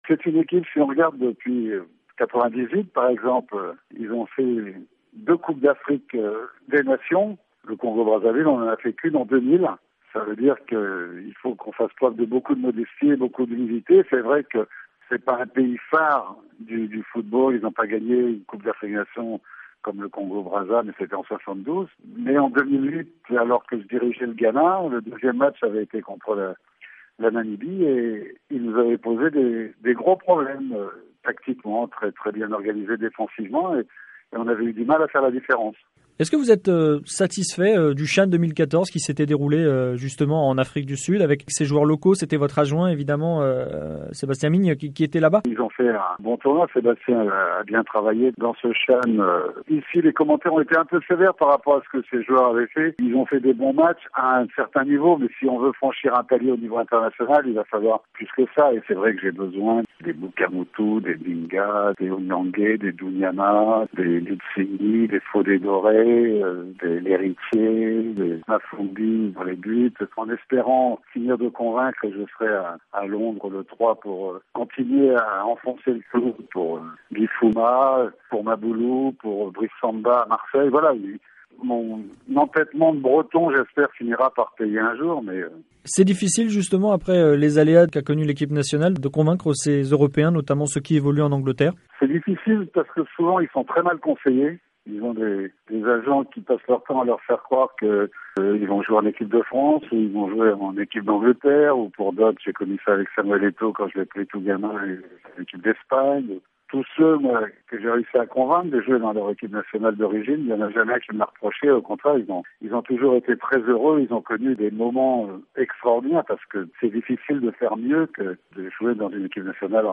C’est dans la capitale égyptienne, siège de la Caf, qu’a eu lieu le tirage au sort des éliminatoires de la CAN 2015   Il y aura encore deux tours de barrages avant d’accéder à la phase de poules   Dans 3 semaines, les matches du 2e tour auront lieu   Les Diables Rouges Congolais iront défier la Namibie   Un match piège selon Claude Leroy, le sélectionneur du Congo-Brazzaville   Claude Leroy, sélectionneur du Congo joint à Brazzaville